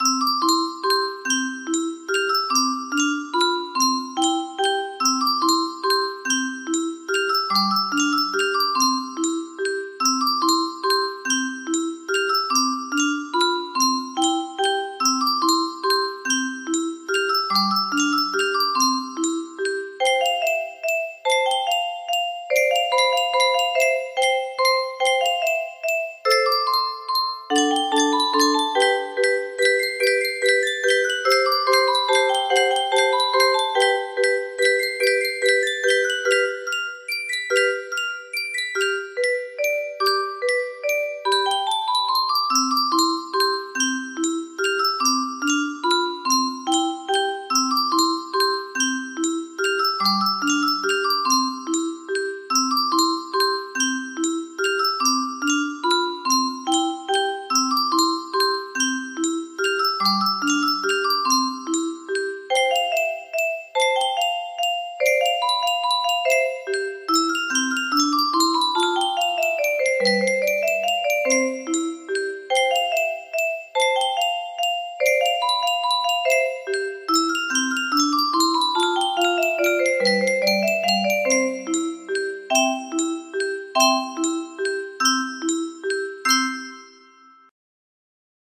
Muzio Clementi - Sonatina Op. 36 No. 1 3rd Movement Vivace music box melody
Full range 60